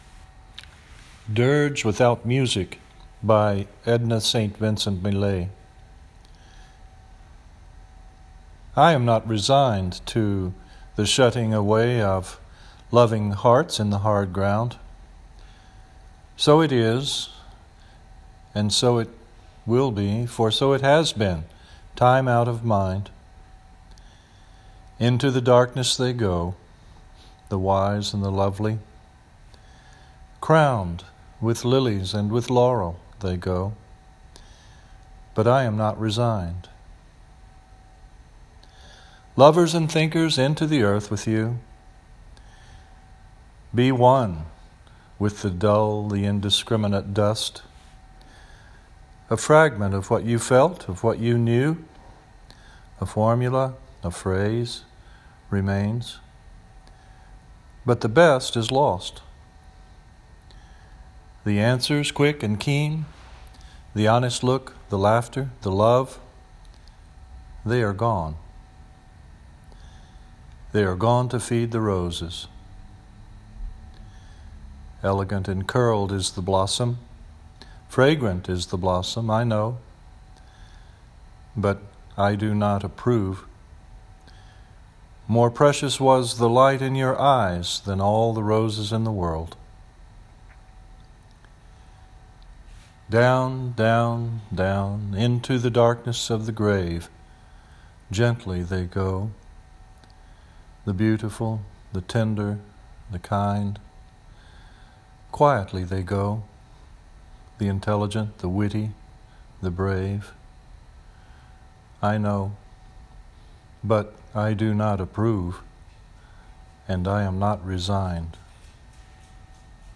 From Memory